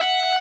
guitar_015.ogg